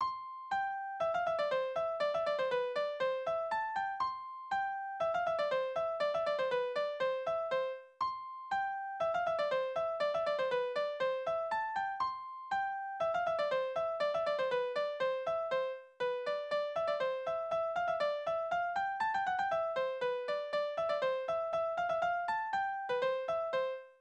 « 10925 » Marschrheinländer Tanzverse: Tonart: C-Dur Taktart: 2/4 Tonumfang: kleine None Besetzung: instrumental Externe Links